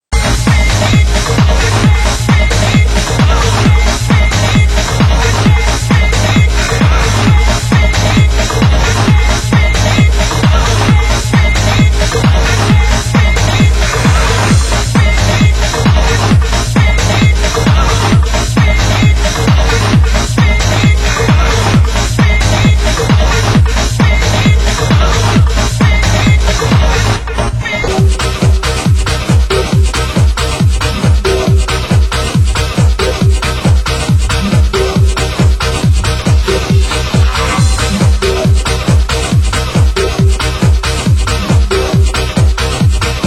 Genre: Funky House